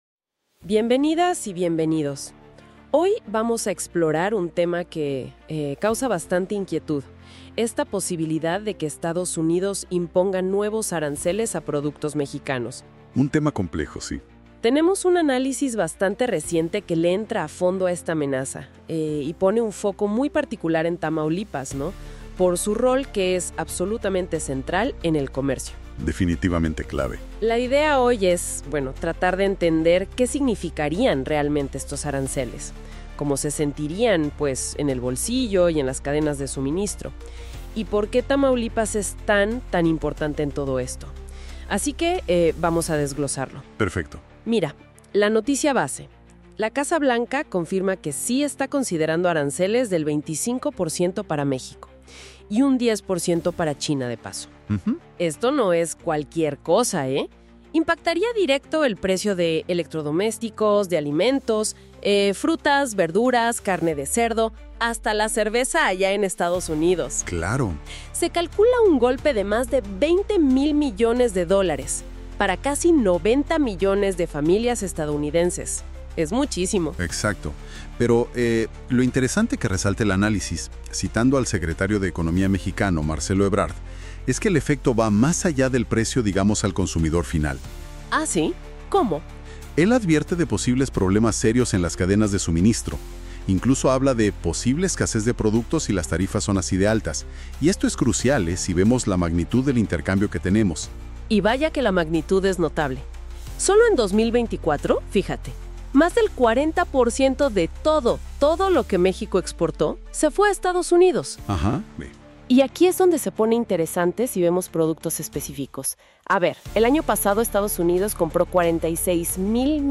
Reportaje_mezcla.mp3